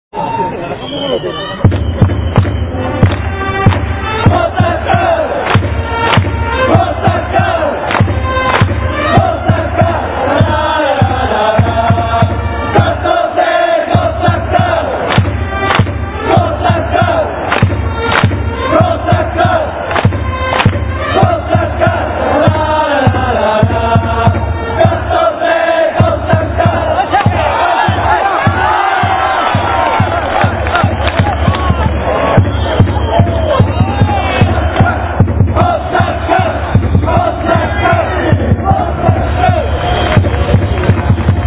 - 過去に使われていた応援歌 -